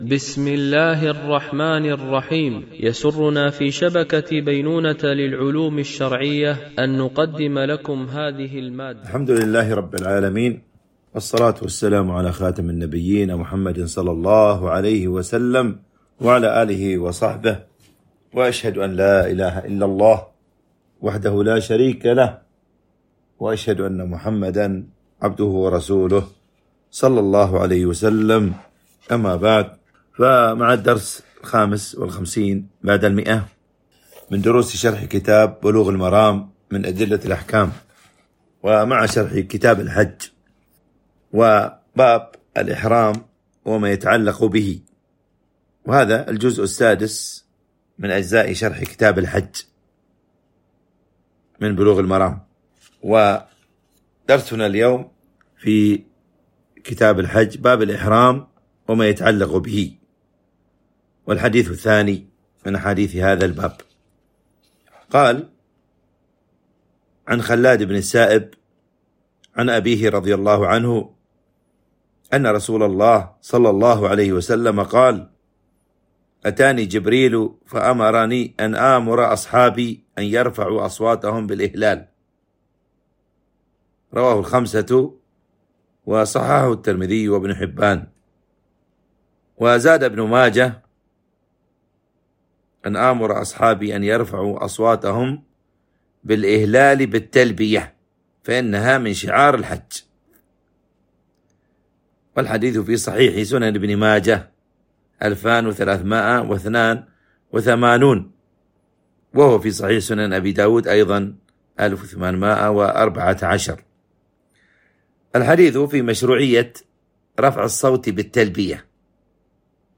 التنسيق: MP3 Mono 44kHz 64Kbps (VBR)